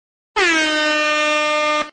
Air Horn Meme Effect sound effects free download